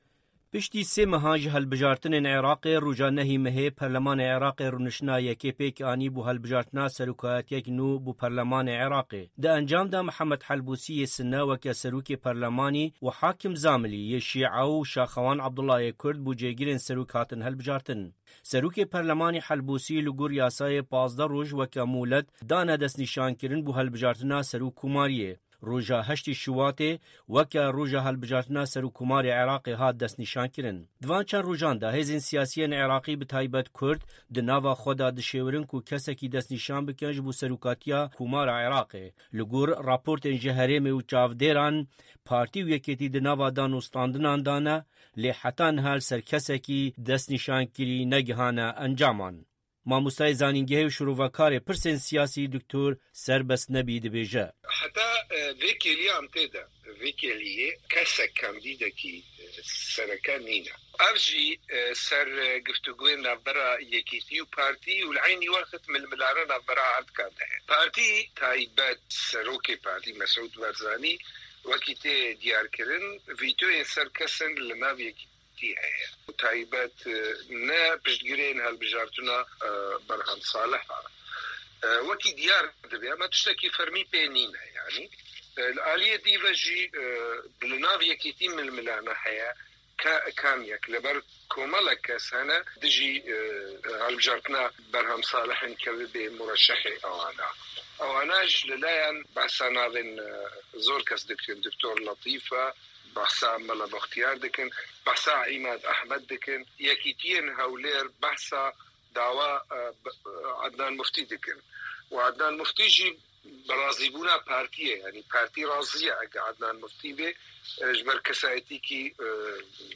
Raporta taybet derbarê berbijariya serokomariya Îraqê